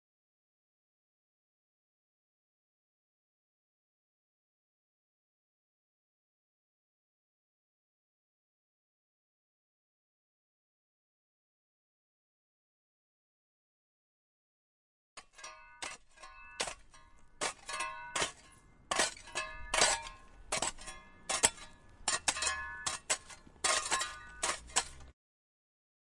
用铲子挖地
描述：用小铲子在地上挖。在一个炎热的日子里，用Zoom H1在路边录制的。
标签： 地面
声道立体声